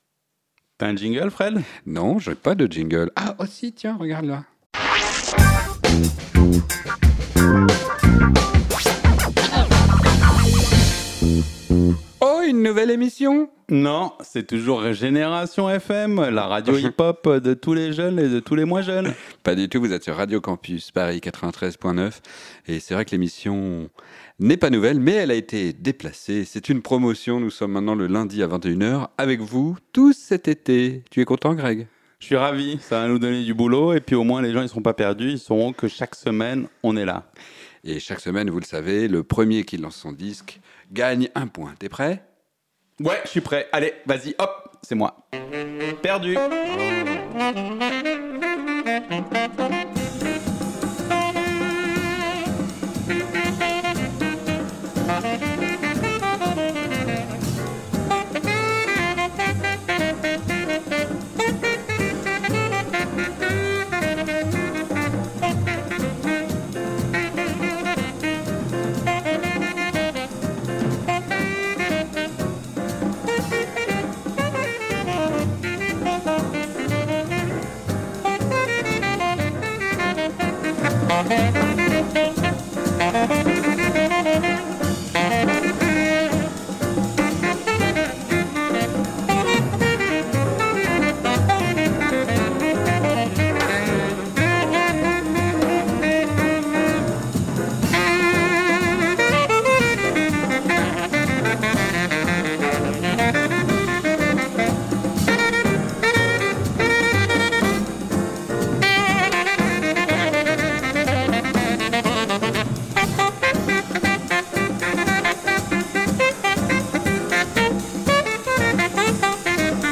Classique & jazz